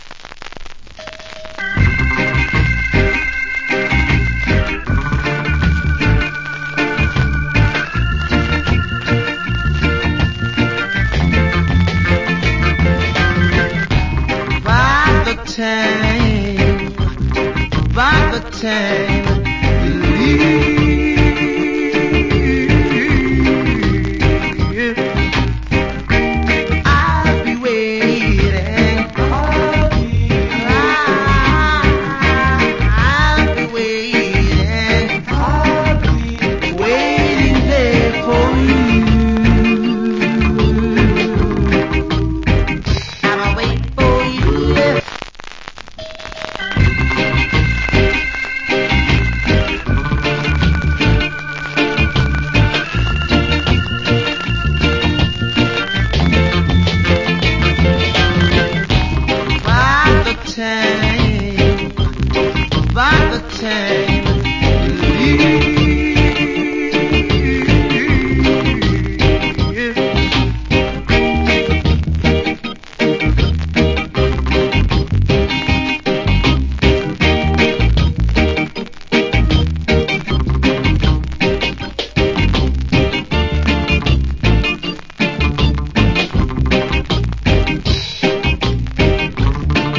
Great Reggae Vocal.